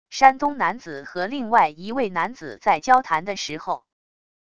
山东男子和另外一位男子在交谈的时候wav音频